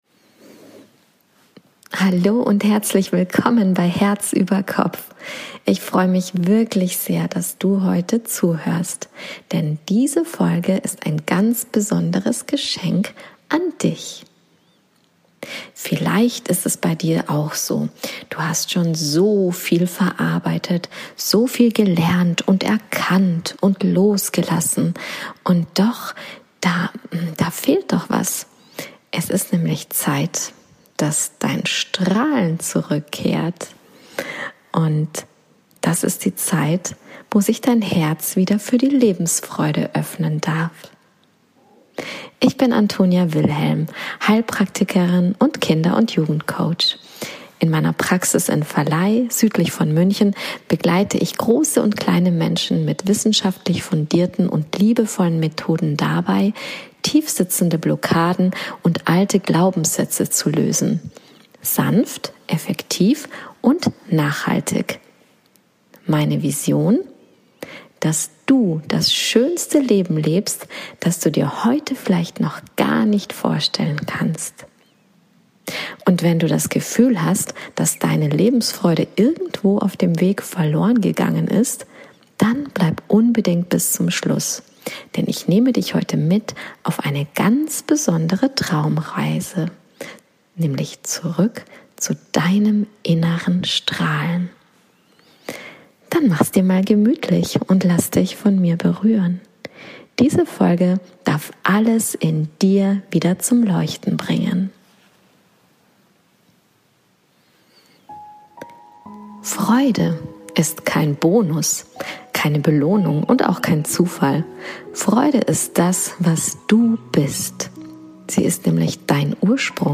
Traumreise: Zurück zu deinem inneren Licht